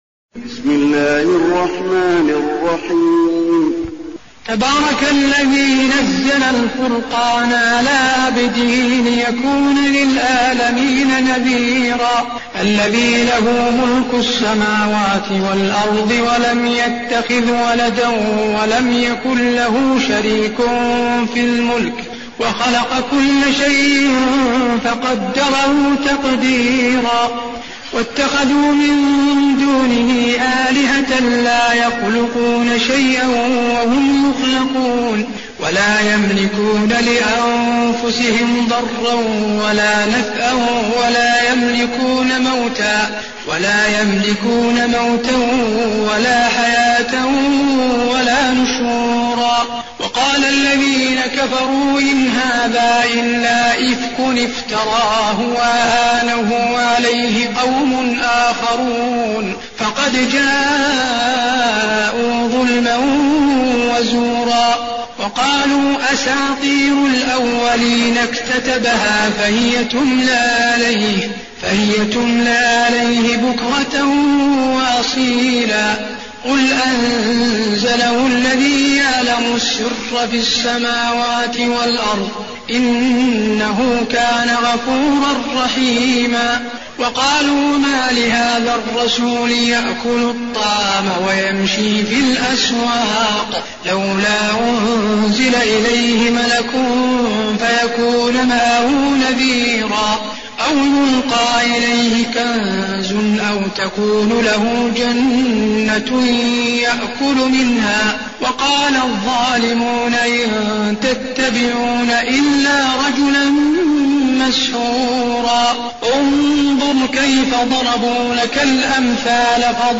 المكان: المسجد النبوي الفرقان